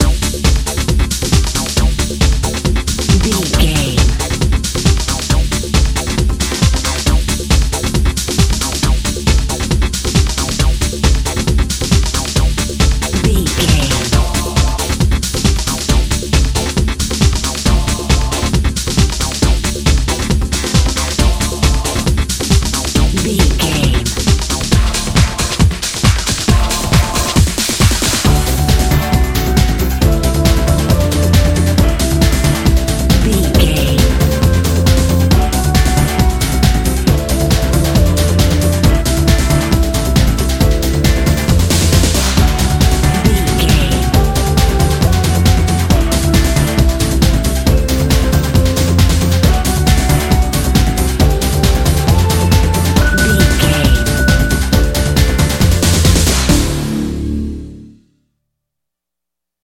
Ionian/Major
Fast
synthesiser
drum machine
Eurodance